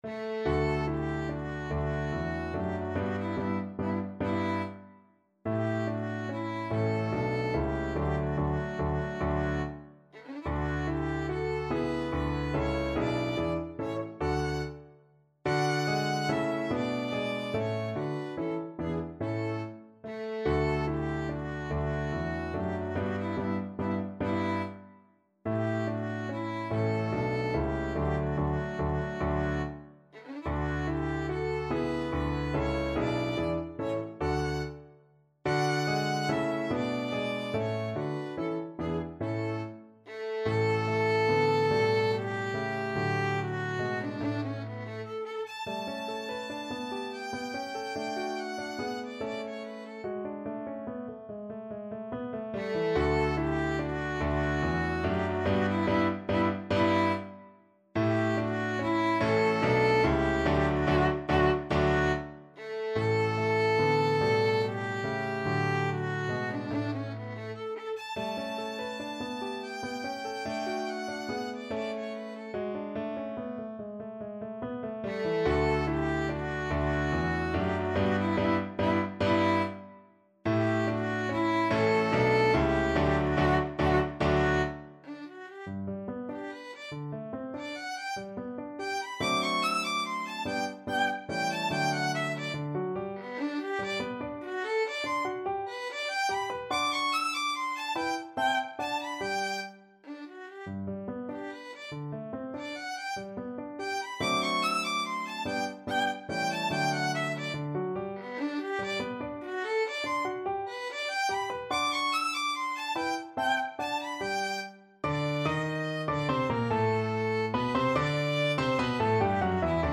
Violin
3/4 (View more 3/4 Music)
D major (Sounding Pitch) (View more D major Music for Violin )
Moderato =c.144
Classical (View more Classical Violin Music)